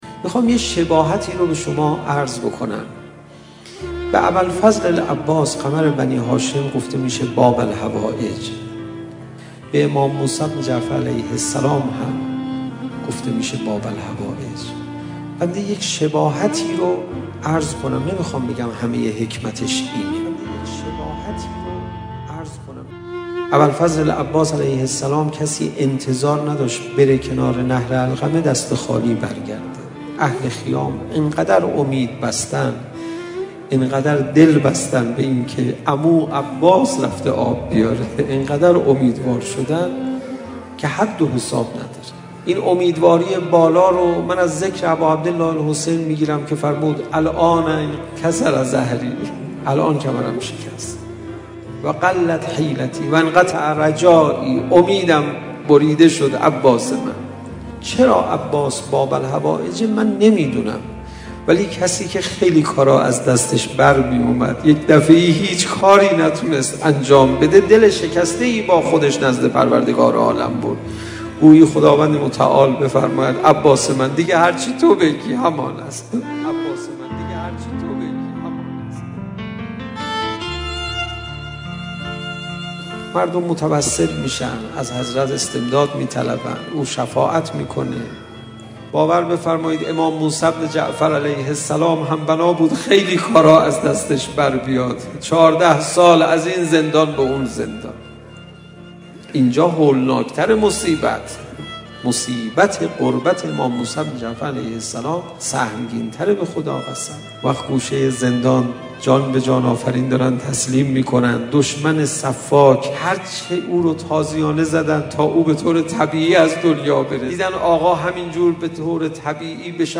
سخنرانی و پادکست مذهبی